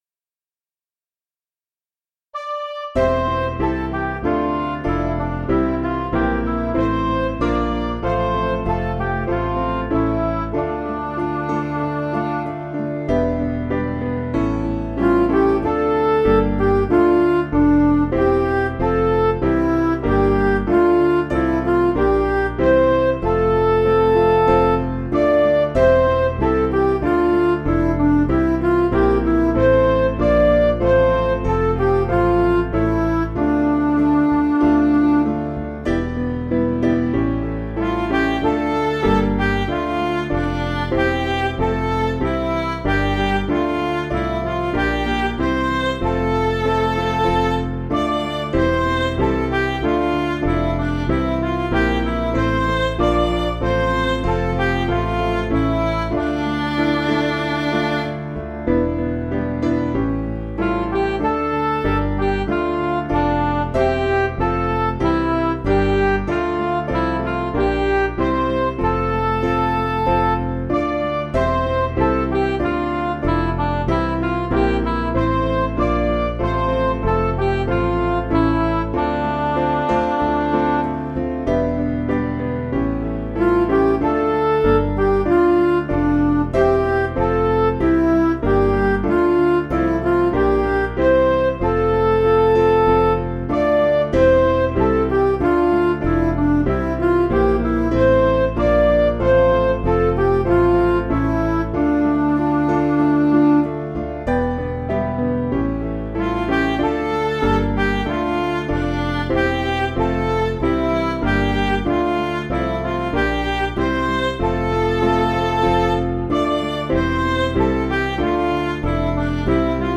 Piano & Instrumental
(CM)   8/Dm